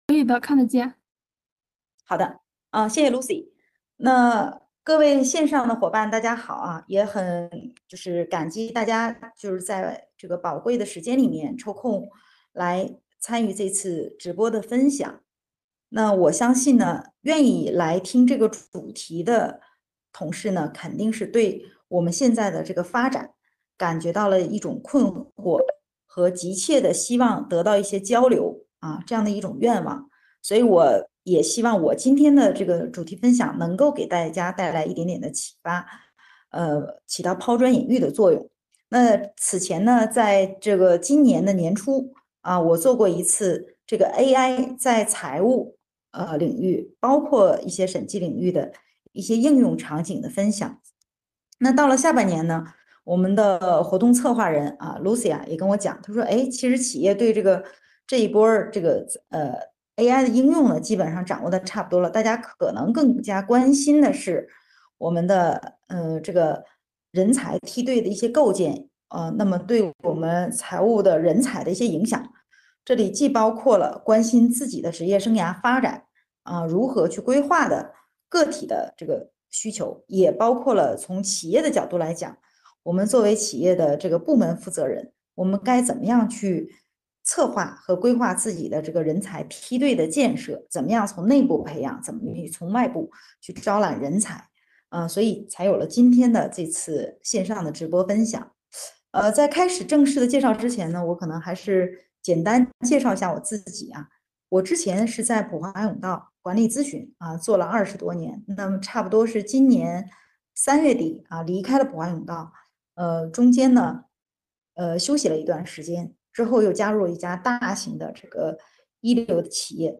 视频会议
14:00 主持人开场